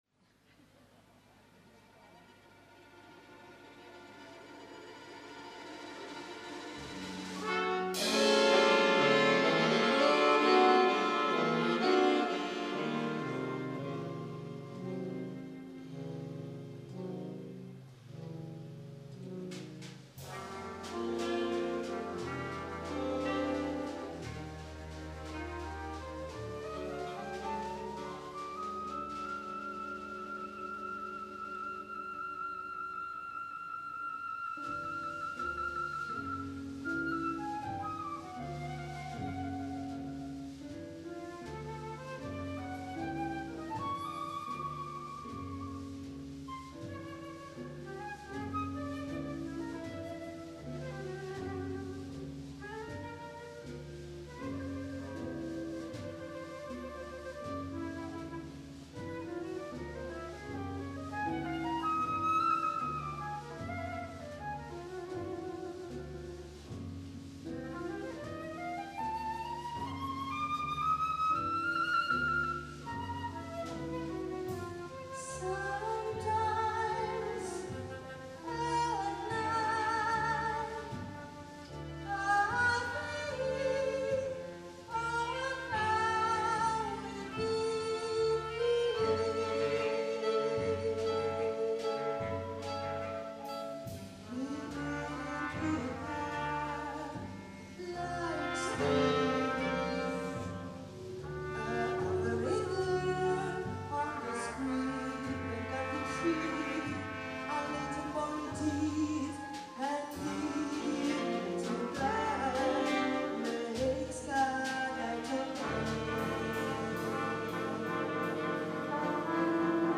Nel 1997 mi chiese di far parte, in qualità di violinista, a una big band da lui diretta, chiamata Factory Orchestra, composta da splendidi musicisti quasi tutti di area genovese.
cantante
tromba
sax
È possibile che prima o poi mi dedichi a migliorare la resa audio di quest’unica presa diretta rimasta di un’esecuzione pubblica (non priva, a mio avviso, di momenti validi).